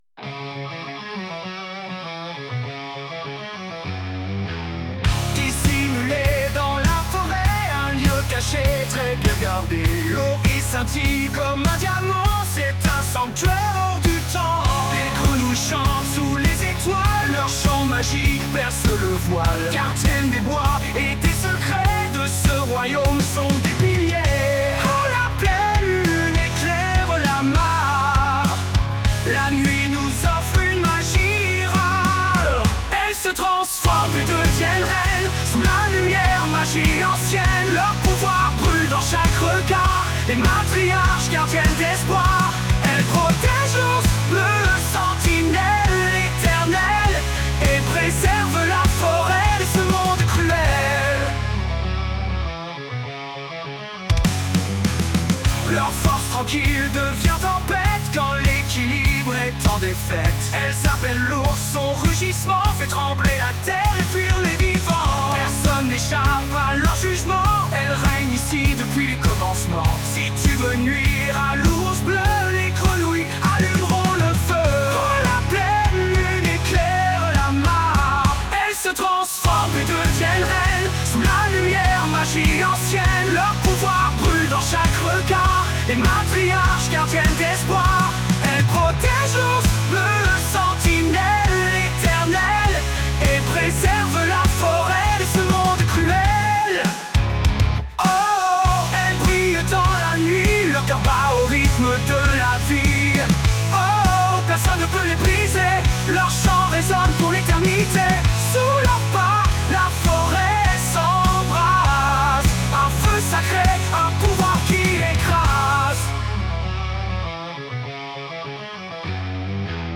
un hymne rock riche en détails et en émotions.